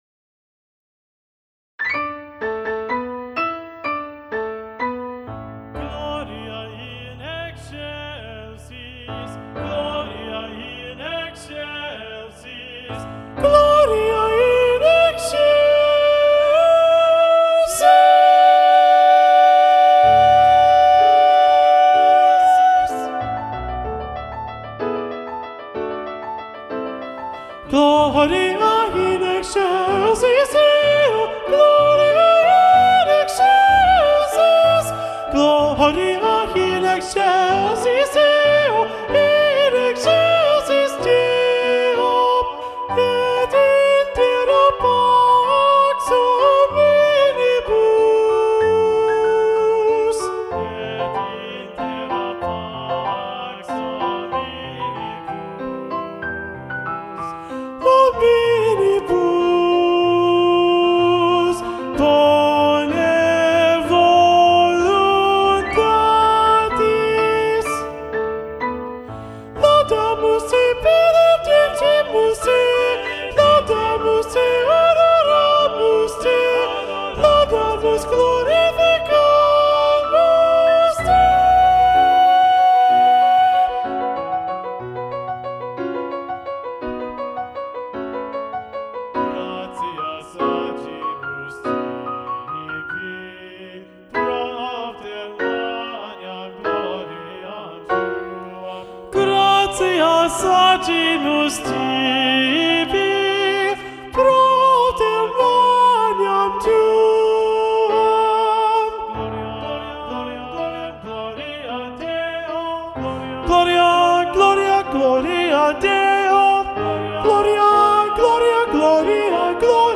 Lo ULTIMO sopranos
Gloria-Missa-Festiva-SATB-Soprano-Predominant-John-Leavitt.mp3